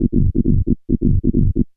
K-7 Bassline.wav